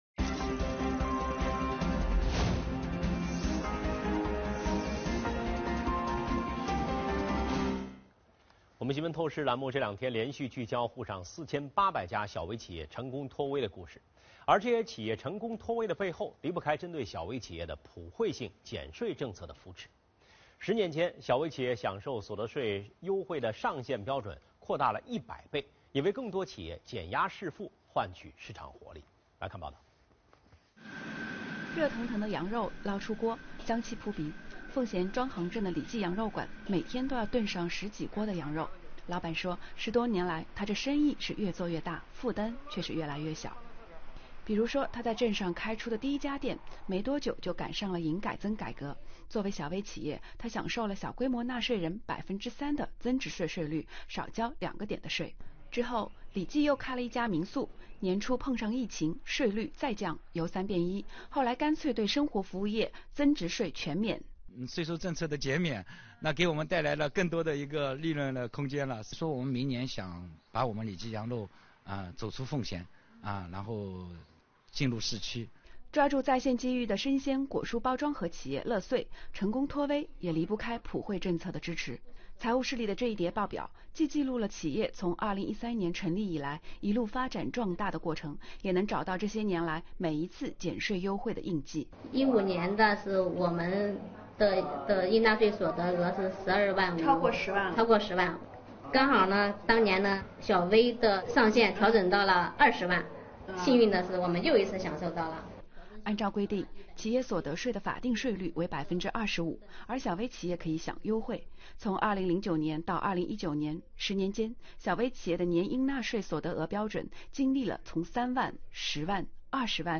本周，上海电视台《新闻透视》栏目推出3集系列报道，探寻多家小微企业脱“微”背后的秘笈。